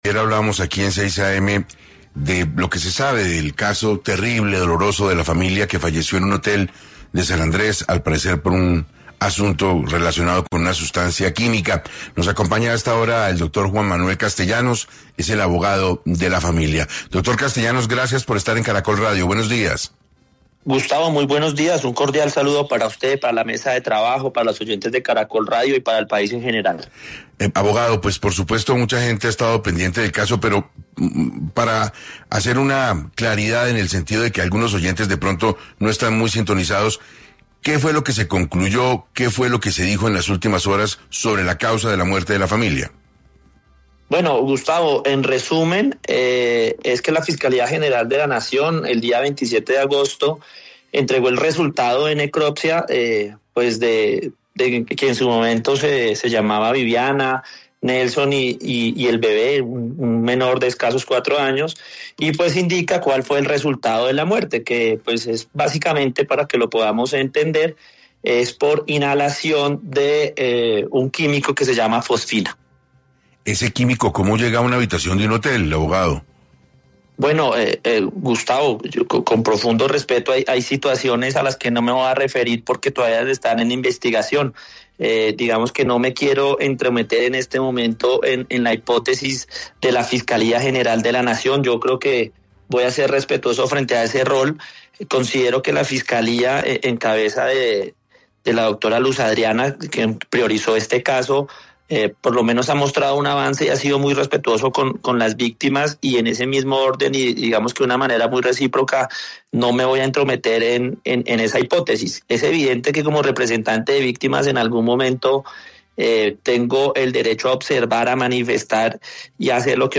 En diálogo con 6AM de Caracol Radio